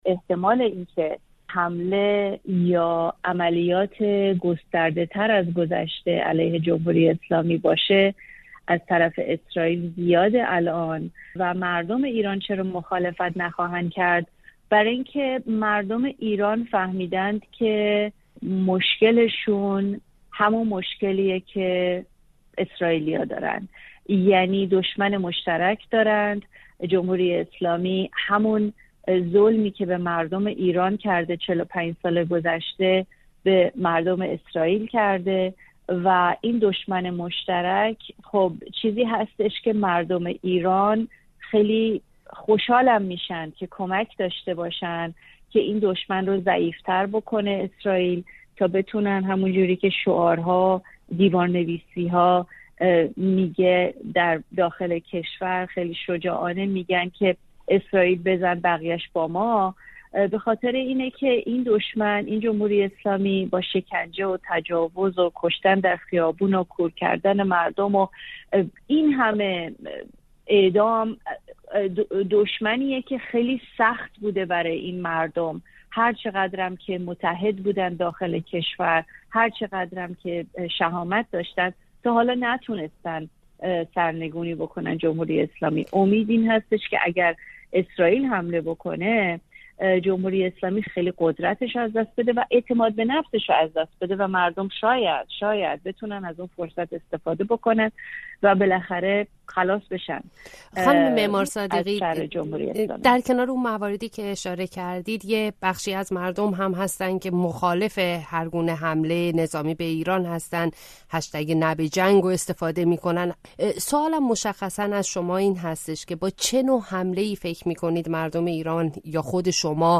گفتگو با یک فعال سیاسی‌ که می‌گوید اسرائیل باید پایگاه‌های سپاه را بزند